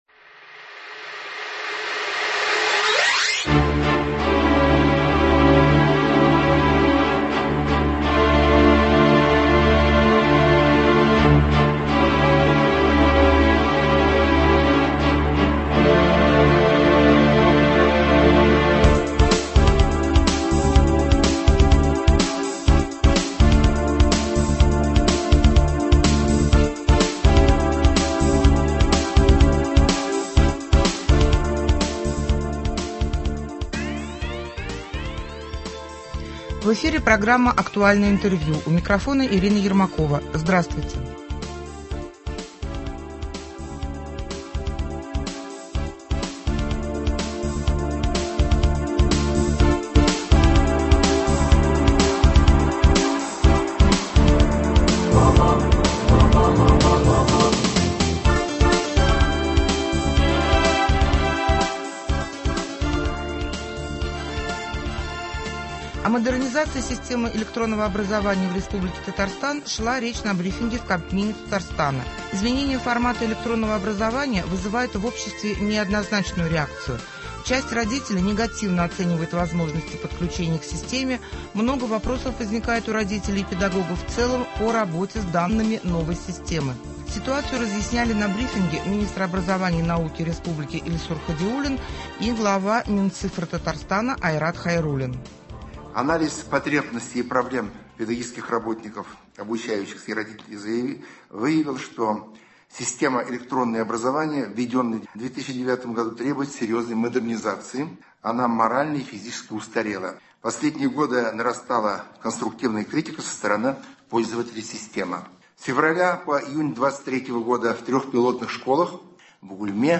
Ситуацию разъясняли на брифинге Министр образования и науки Республики Татарстан Ильсур Хадиуллин и Глава Минцифры Айрат Хайруллин.